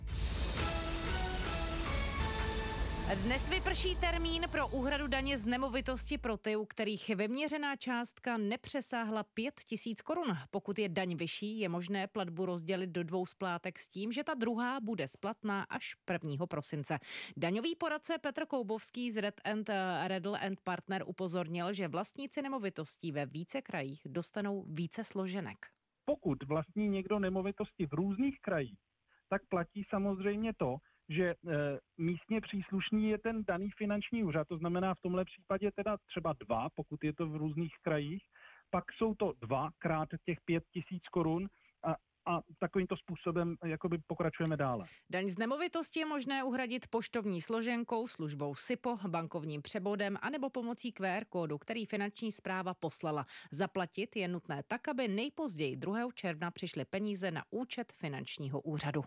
rozhovor k dani z nemovitých věcí